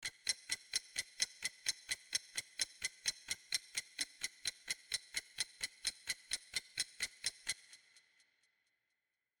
Download Clock sound effect for free.
Clock